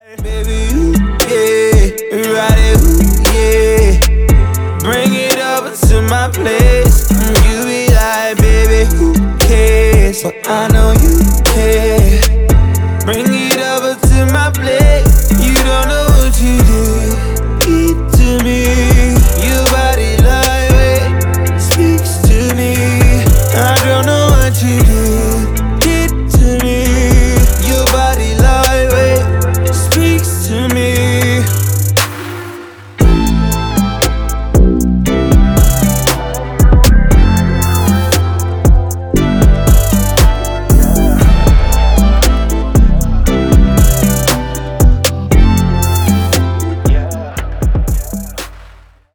• Качество: 320, Stereo
атмосферные
приятные
RnB
приятный голос
заедающие